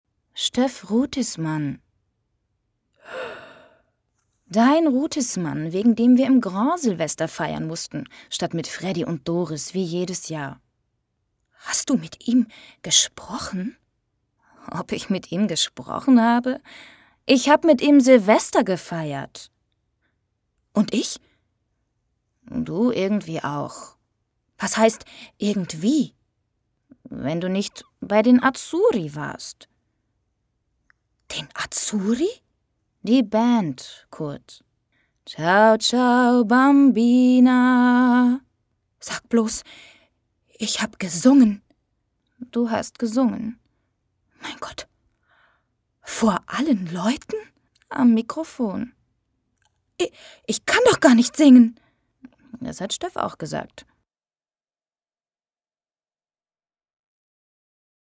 Sprechprobe: Werbung (Muttersprache):
german female voice over artist